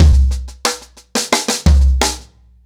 Wireless-90BPM.49.wav